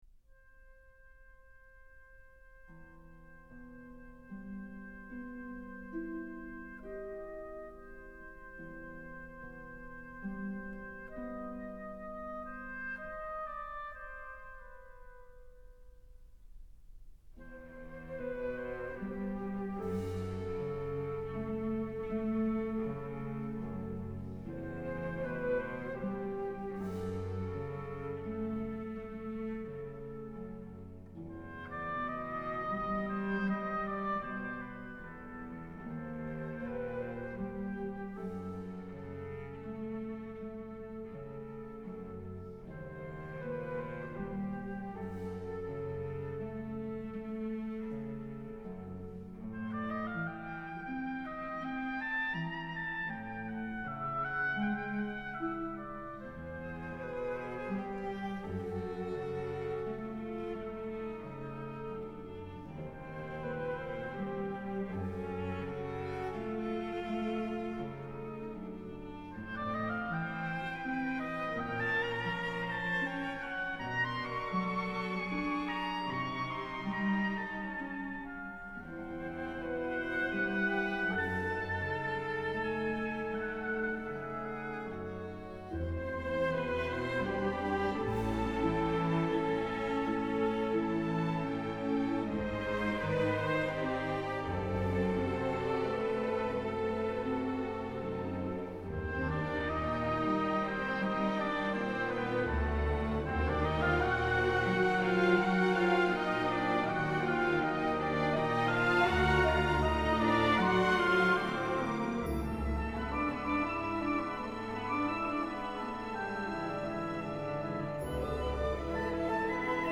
As the strings and winds moved slowly yet inexorably toward the brass fanfare announcing the arrival of Satan, I could feel the goosebumps on my arms and the hair on the back of my neck stand up. And when the full orchestra finally unleashed its triple-fortissimo fury, my spine was practically electric.